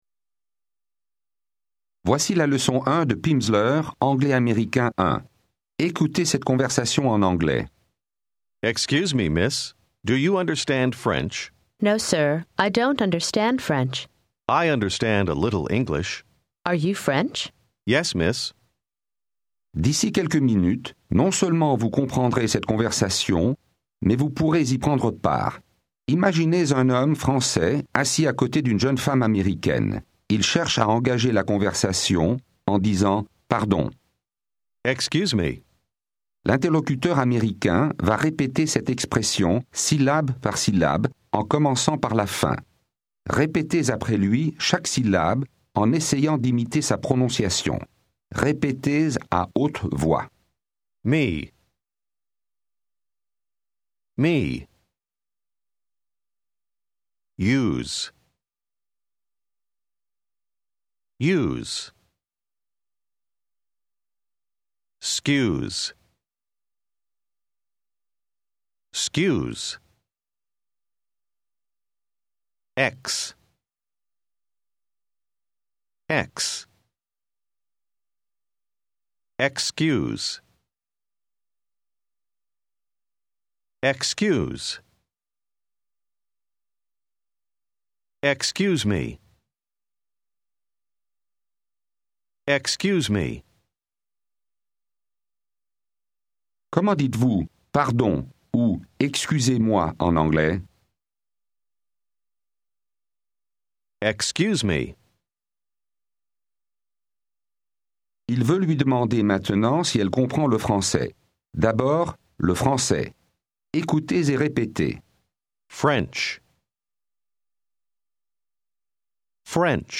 English for French Speakers Phase 1, Unit 1 contains 30 minutes of spoken language practice, with an introductory conversation, and isolated vocabulary and structures.